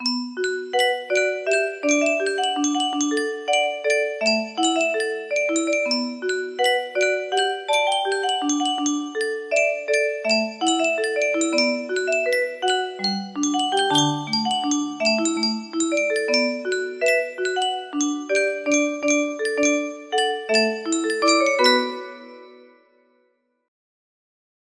This was a song that NEEDED to be played on a music box.